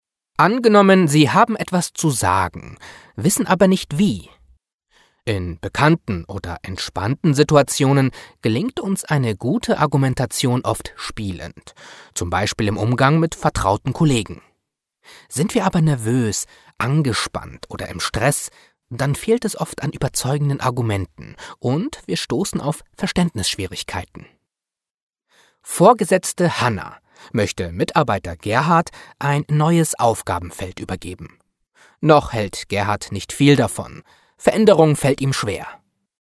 E-Learning „Die 7er-Kette“